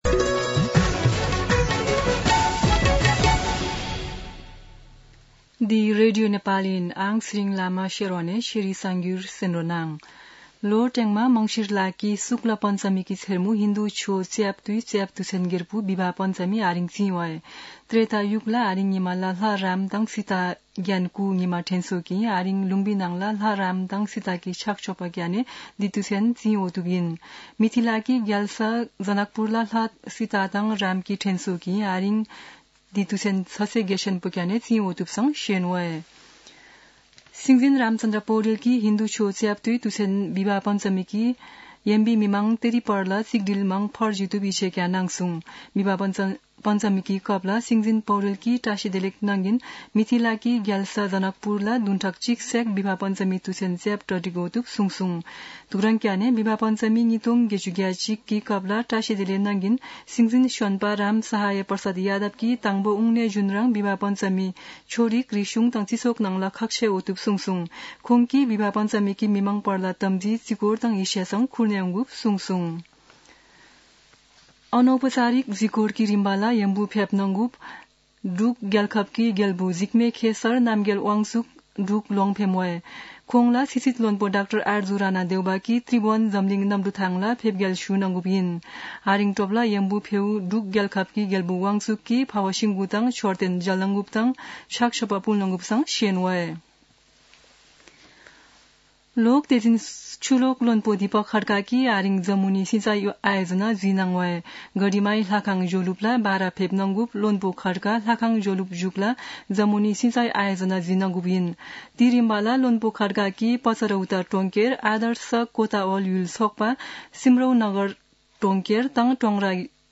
शेर्पा भाषाको समाचार : २२ मंसिर , २०८१
Sherpa-News-21.mp3